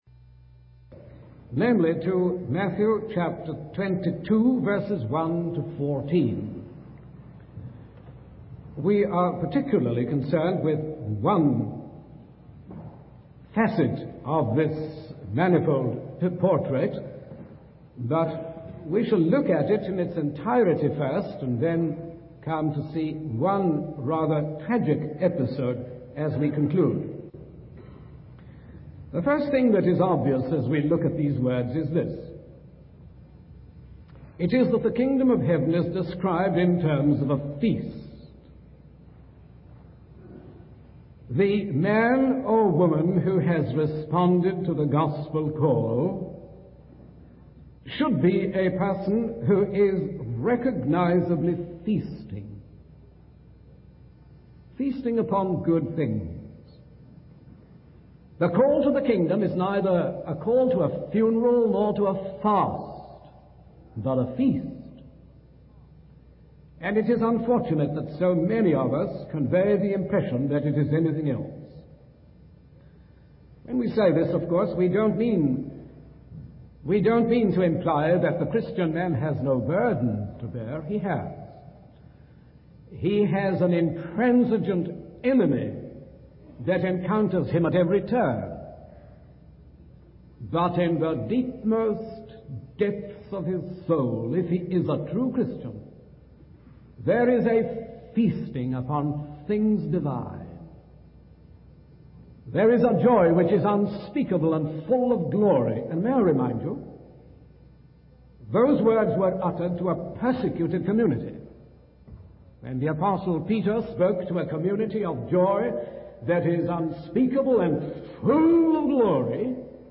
In this sermon, the preacher focuses on the theme of the unheeded invitation found in the early verses of the passage. He explains that the call to the feast was rejected by those who made light of it and chose to prioritize their own interests.